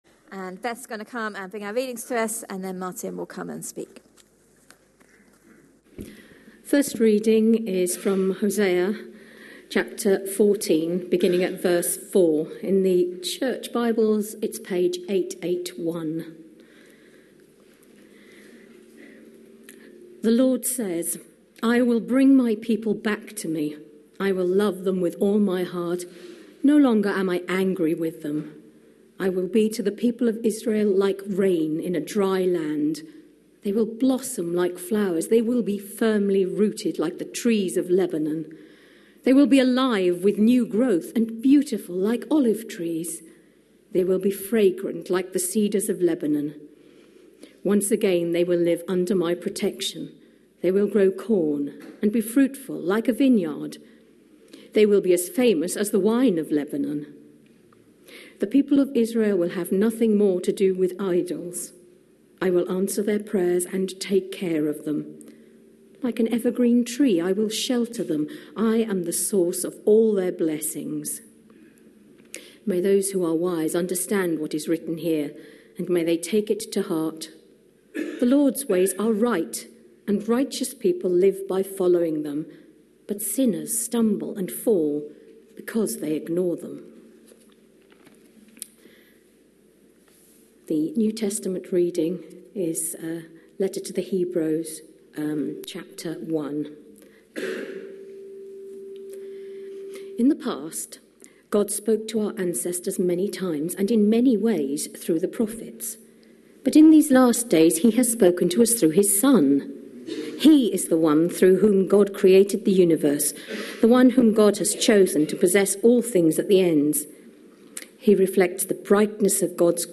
A sermon preached on 7th February, 2016, as part of our Lent 2016. series.